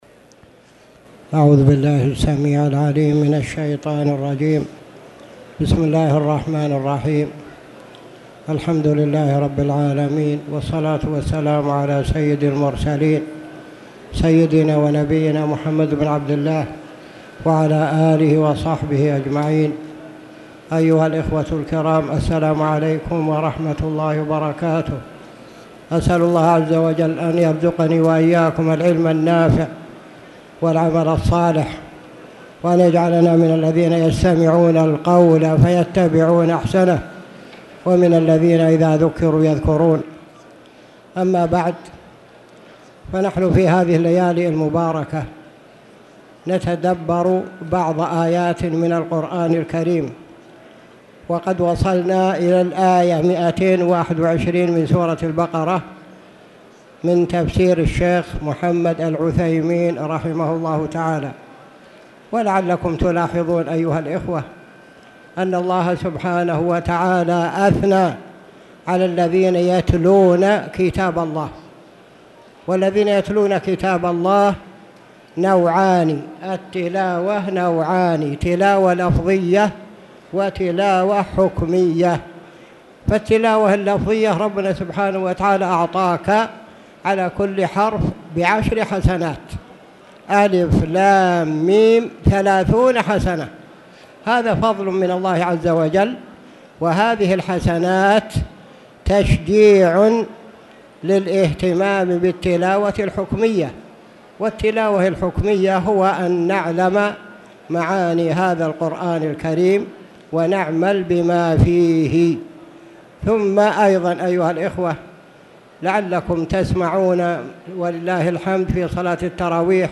تاريخ النشر ١٥ رمضان ١٤٣٧ هـ المكان: المسجد الحرام الشيخ